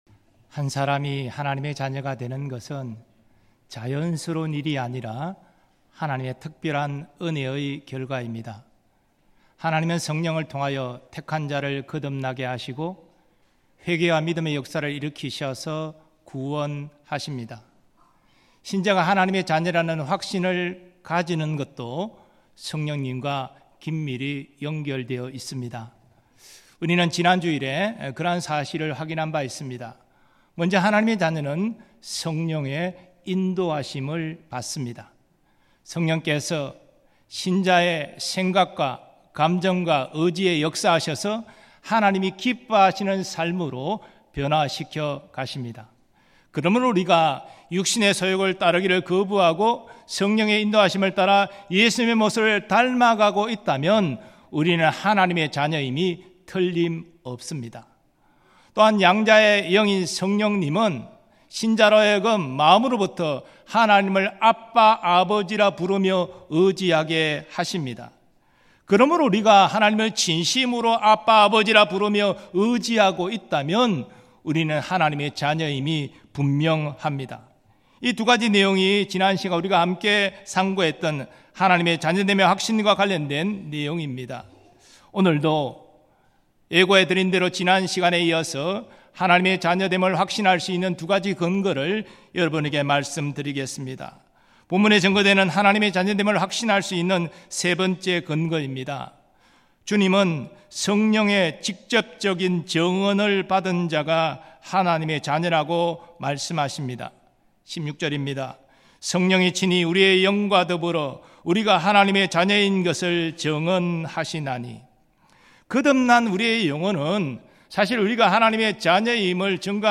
주일예배말씀
음성설교 듣기 MP3 다운로드 목록 이전 다음